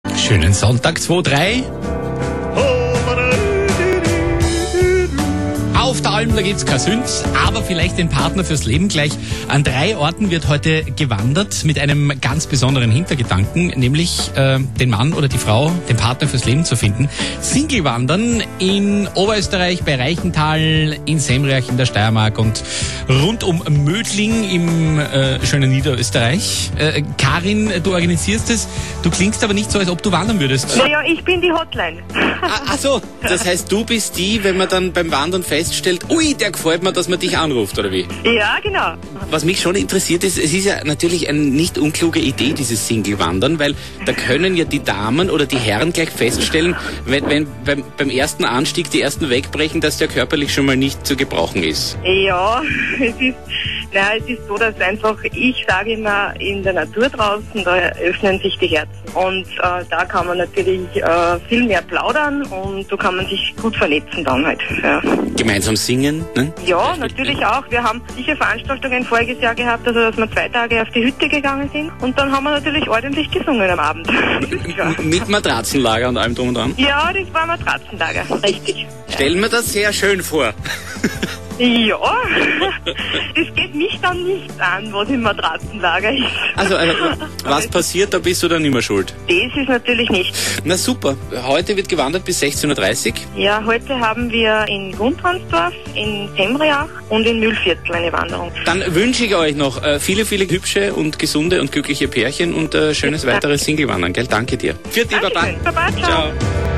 Ö3 Singles Wandern Österreich Interview von Gerald Fleischhacker
Nochmals Daaaanke an Gerald Fleischhacker und das gesamte Hitradio Ö3-Team für das nette Interview, das im Rahmen des Ö3-Sonntagsradio am 17.Mai 2009 um ca. 14:15 österreichweit ausgestrahlt wurde :-)